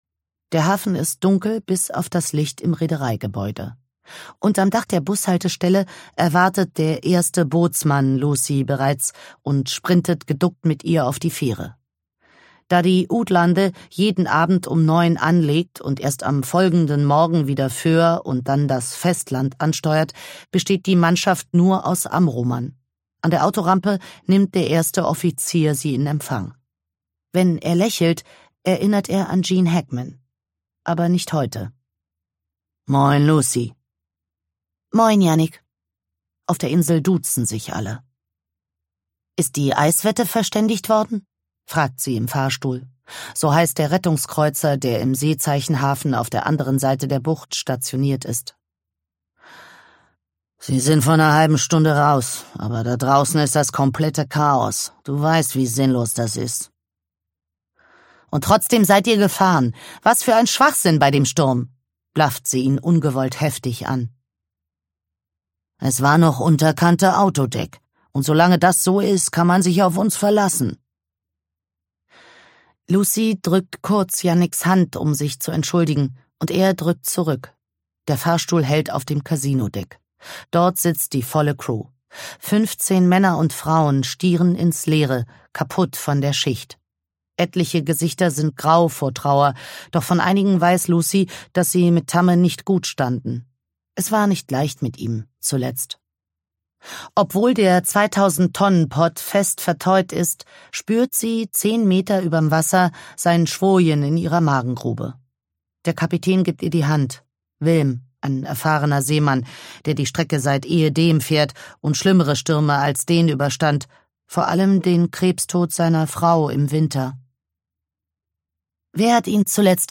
Ungekürzte Lesung Random House Audio